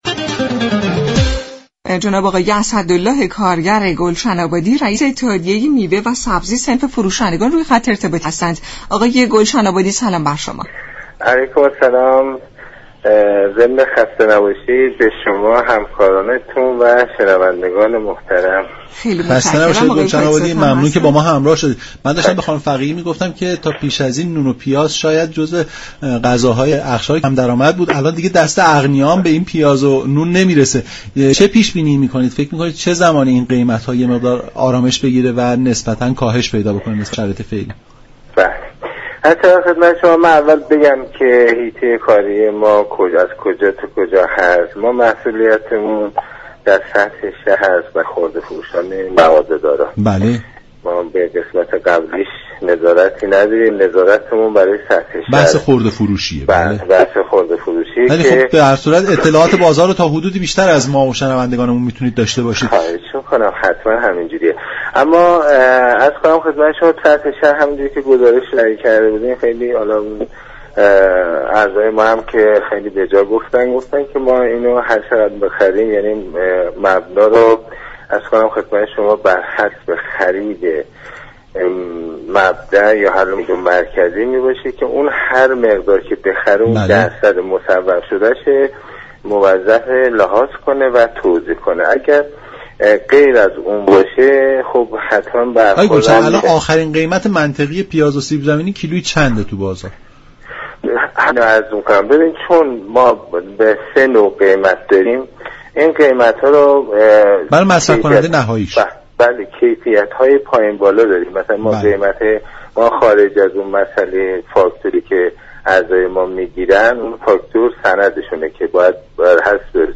در گفت و گو با برنامه نمودار رادیو ایران